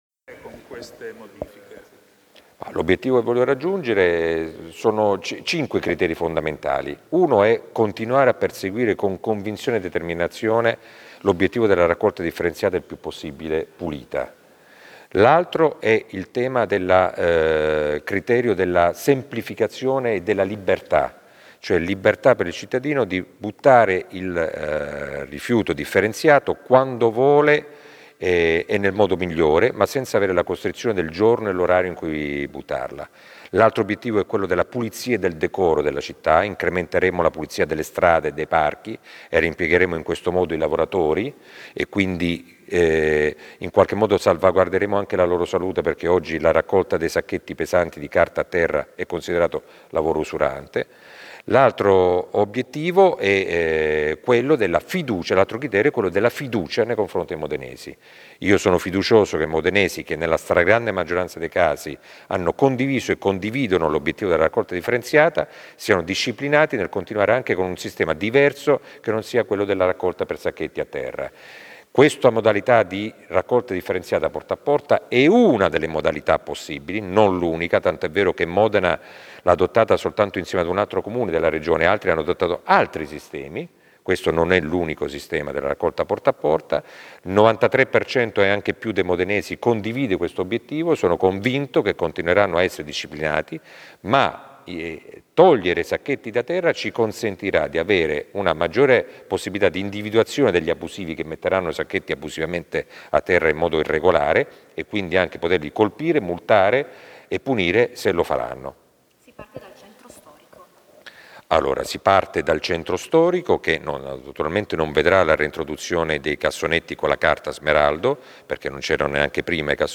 CLICCA QUI PER ASCOLTARE L’INTERVISTA AL SINDACO MEZZETTI